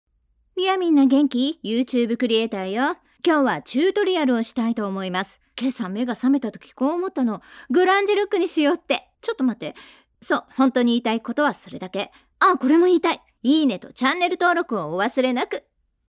Japanese female voices